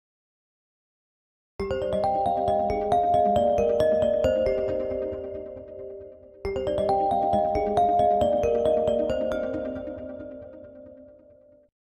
Klingt nach Neunziger-Trance